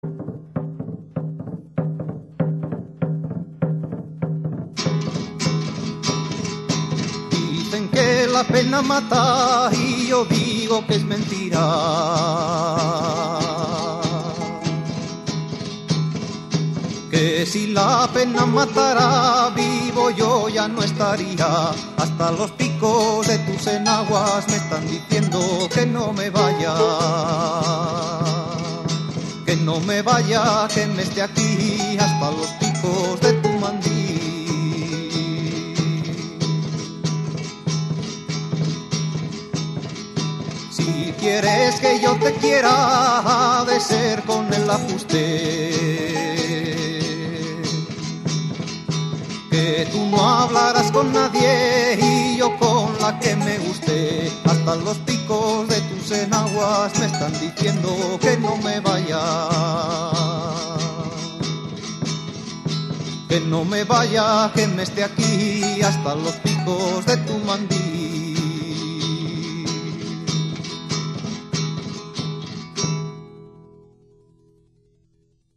Guitarras y voz